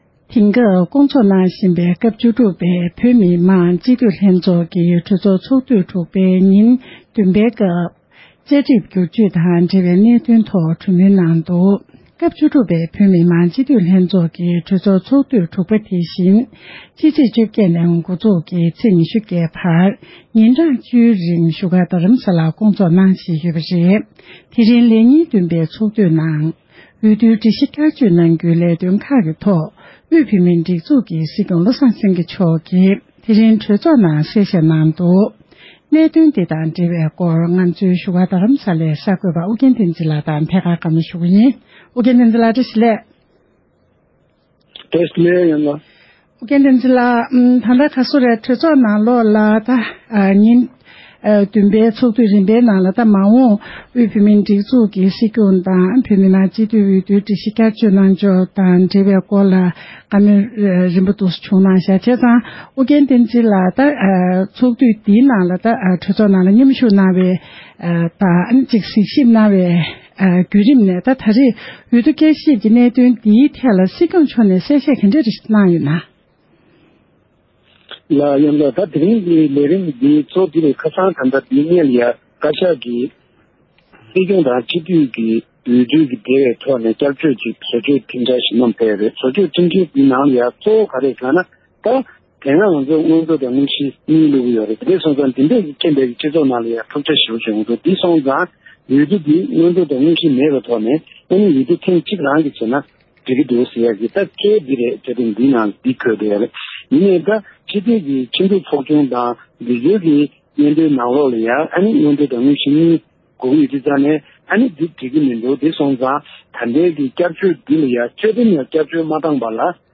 སྒྲ་ལྡན་གསར་འགྱུར། སྒྲ་ཕབ་ལེན།
བཅའ་ཁྲིམས་ལ་བསྐྱར་བཅོས་མ་གནང་བར་ཁྲིམས་འཆར་དེ་བསྐྱར་བཅོས་འགྲོ་ཐབས་མེད། བཅར་འདྲིའི་ལེ་ཚན། སྒྲ་ལྡན་གསར་འགྱུར།